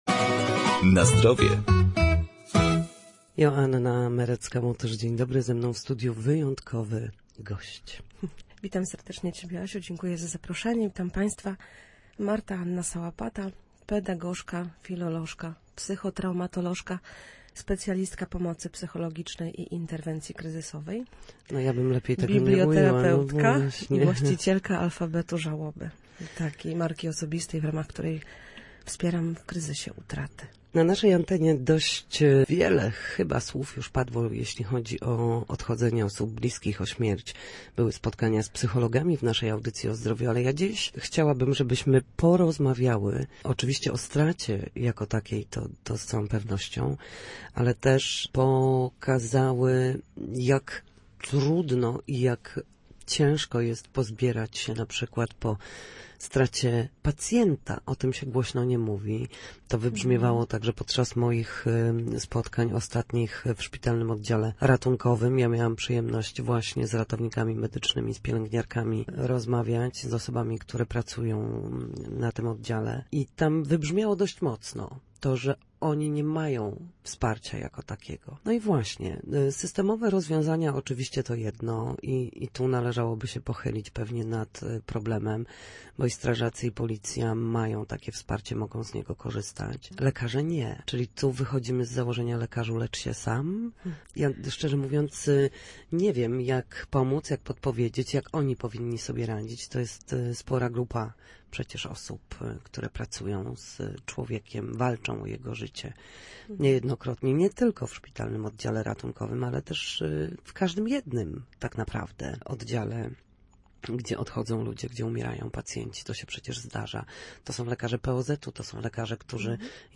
W poniedziałki o godzinie 7:20, a także po 14:30 na antenie Studia Słupsk dyskutujemy o tym, jak wrócić do formy